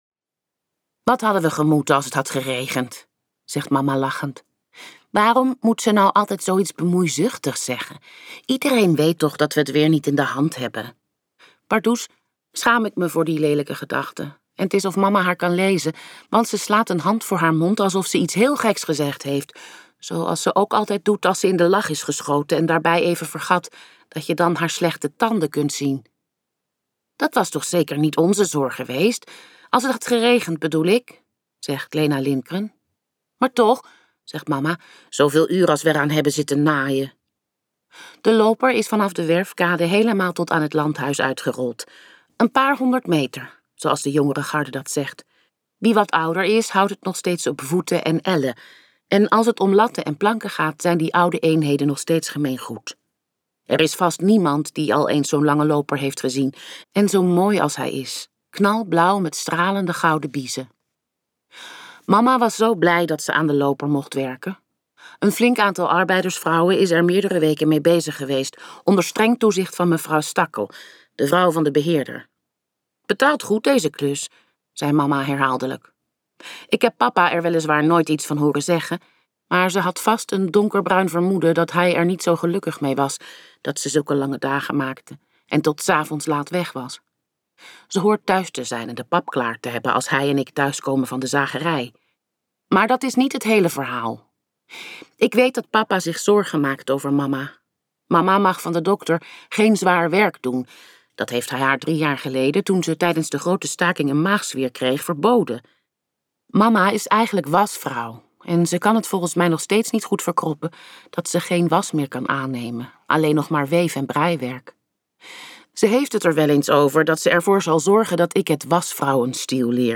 KokBoekencentrum | Het lied van de bomen luisterboek